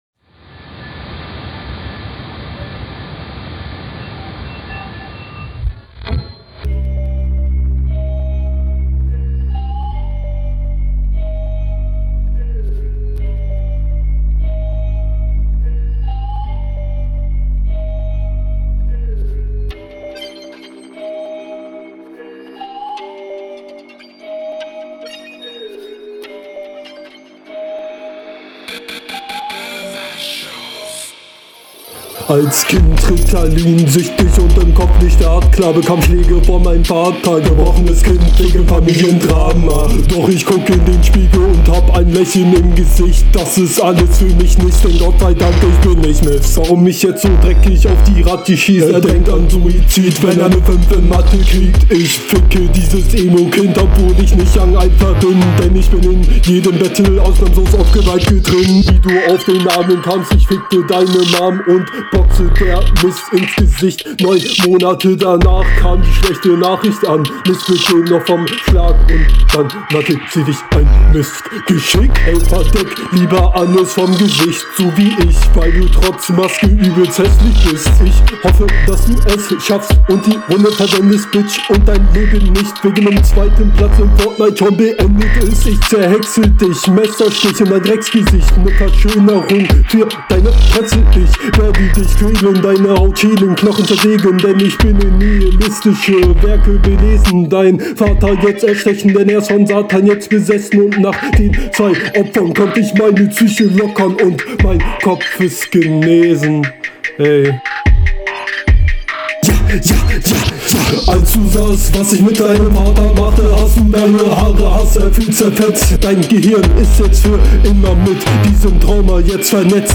Flow: Leider wieder nicht tight.